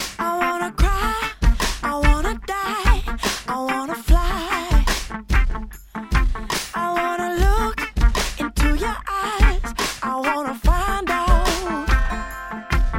A continuación se muestra un ejemplo de una voz sin comprimir en una mezcla. Puedes escucharlo, pero está un poco por detrás del resto del instrumental y algunas palabras no están tan presentes como otras.
Voz en Mezcla Sin Comprimir